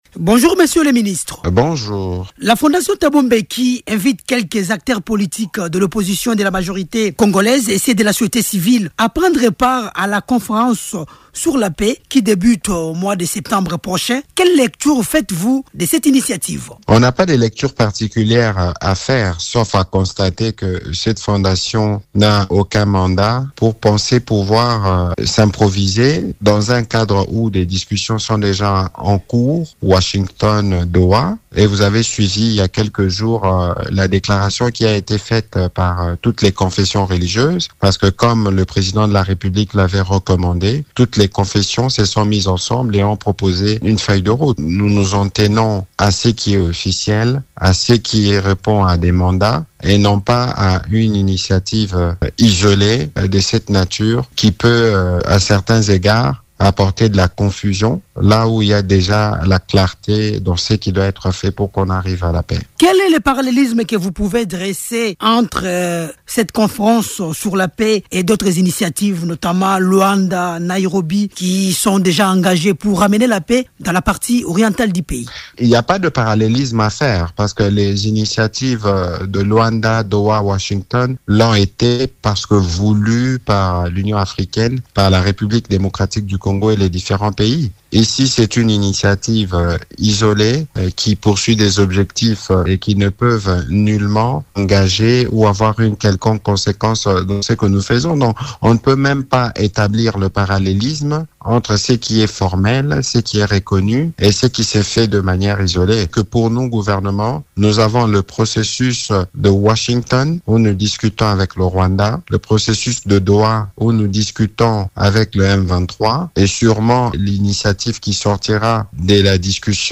Le porte-parole du gouvernement, Patrick Muyaya, juge inopportune l’initiative de la Fondation Thabo Mbeki, qui prévoit de réunir des leaders politiques congolais à une conférence sur la paix et la sécurité début septembre en Afrique du Sud. Il s’est exprimé à ce sujet ce jeudi 28 août, lors d’un entretien accordé à Radio Okapi.